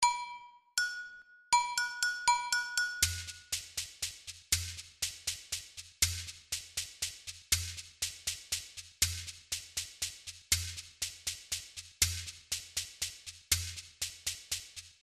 Chékeres
Practice in 6/8